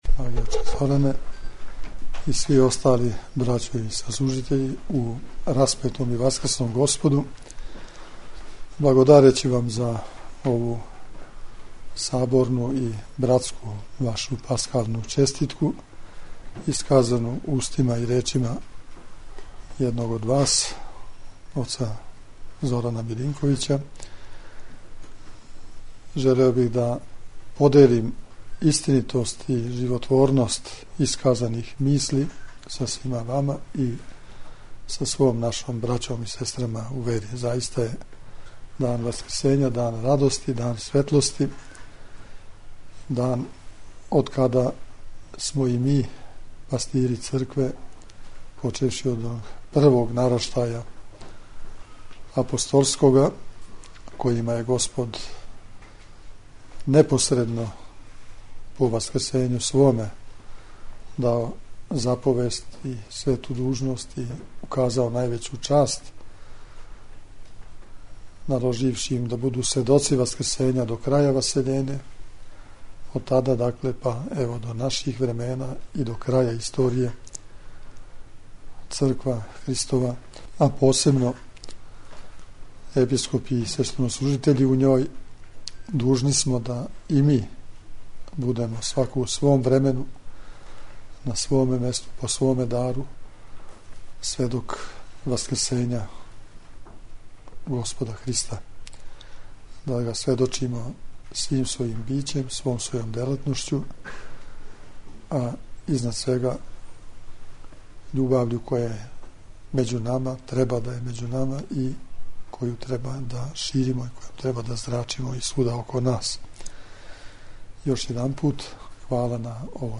Преосвећени Владика очинском поуком је заблагодарио на честитки својим саслужитељима.
Беседа Владике Иринеја
10_cestitka_vaskrsnja_vladika_mono.mp3